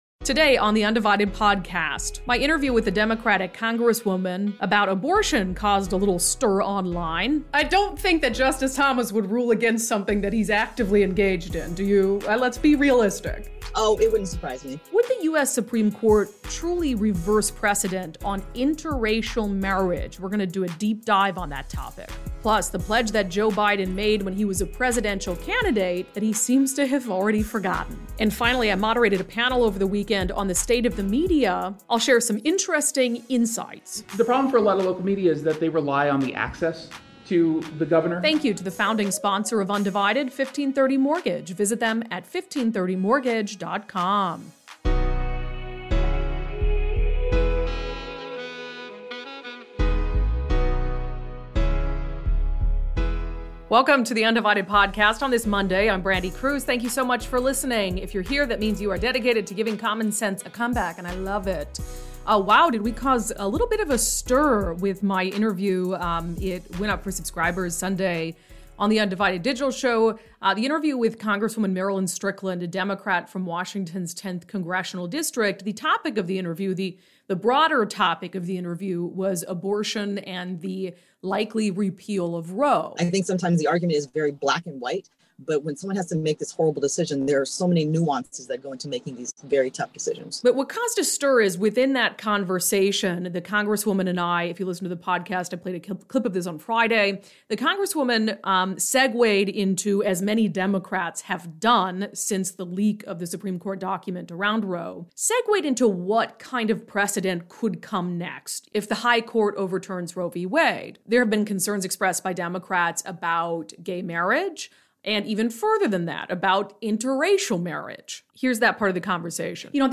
There’s no comparison between precedent on abortion and precedent on interracial marriage. Plus: Joe Biden promised to be a different president. Also: A panel on the state of the media.